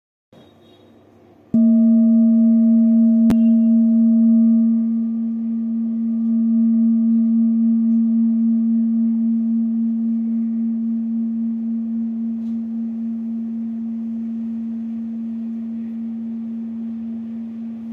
Buddhist Hand Beaten Kopre Singing Bowl, with Antique, Old
Material Bronze
It is accessible both in high tone and low tone .